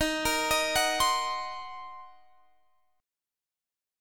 Ebm6 Chord
Listen to Ebm6 strummed